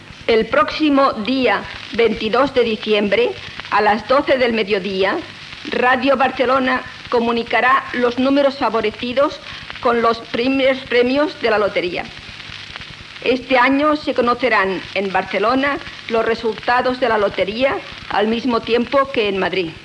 Recreació de l'avís, que el dia 22 de desembre, a l'emissora, es donaran els resultats de la Loteria Nacional.
Informatiu